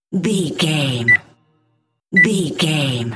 Scanner bar code beep
Sound Effects
urban